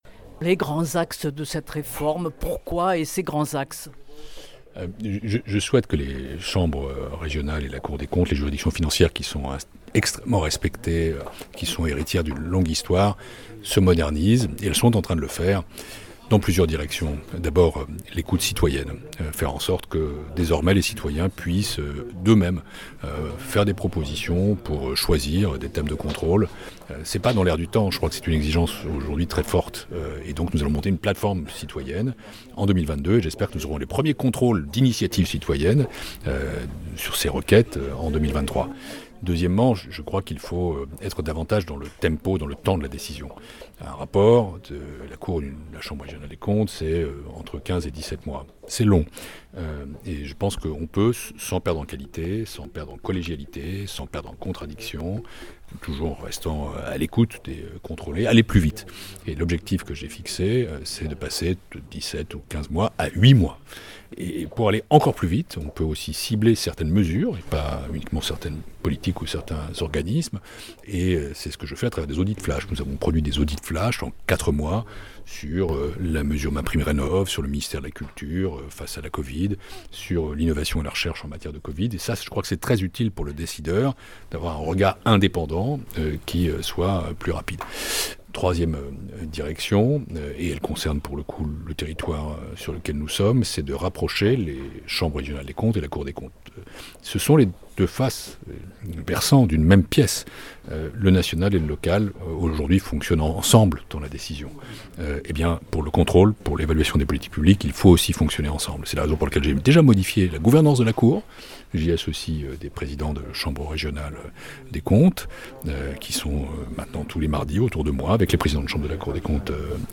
Entretien avec Pierre Moscovici